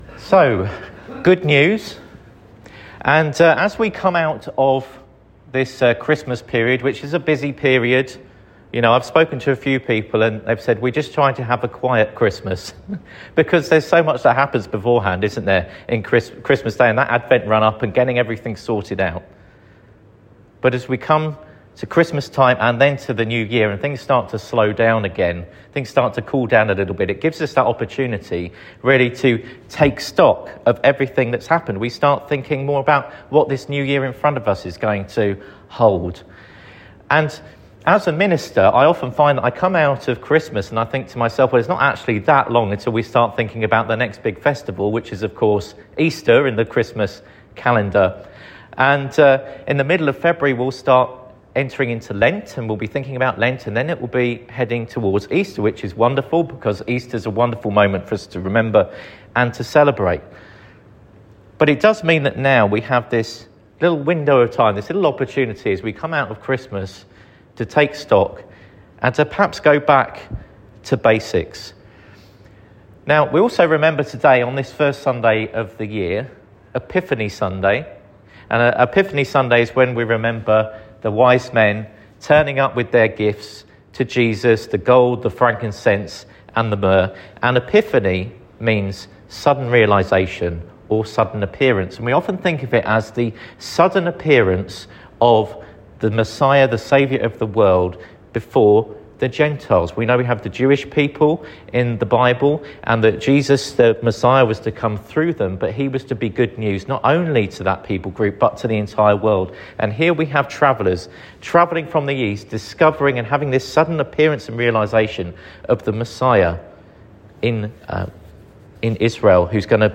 Sermon for Sunday 4th January 2026